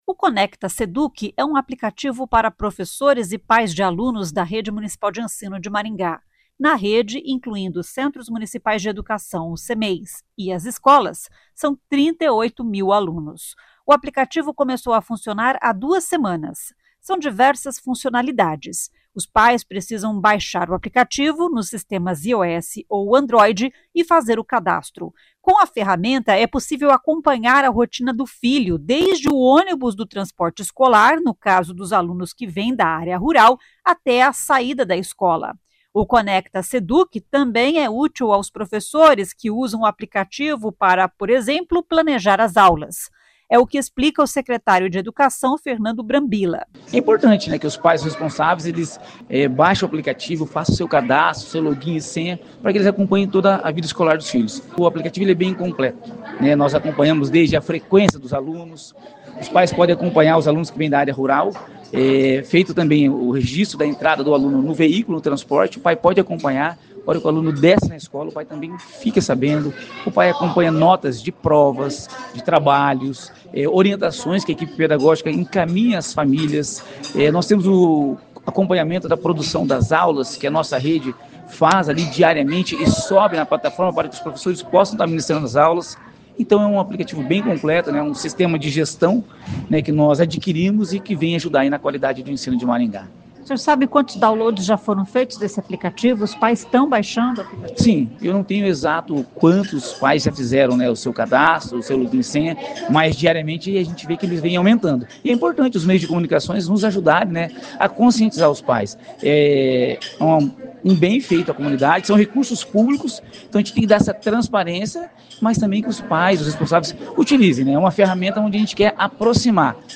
É o que explica o secretário de Educação, Fernando Brambilla.